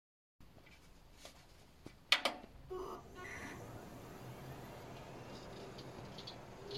Beim booten kommt ein komischer Sound, aber den kann ich leider nicht deuten oder weiß nicht ob er sogar normal ist.
Habe jetzt eine Soundaufnahme hinzugefügt, ist ein komisches surren.
Ja, klingt nach HDD, aber in der Liste ist keine HDD aufgeführt.
Ich hatte vergessen die HDD aufzulisten, habe sie jetzt abgesteckt und das Geräusch ist weg.